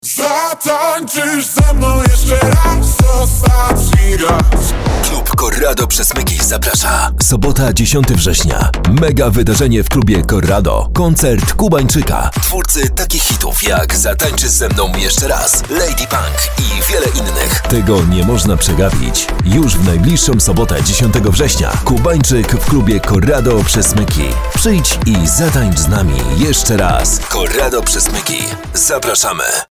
Demo głosowe